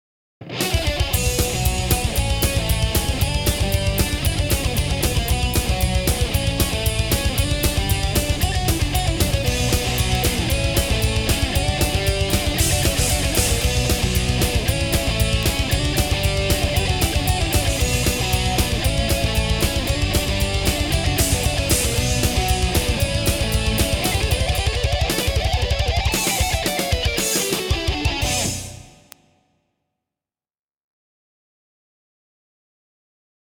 2. 背景音乐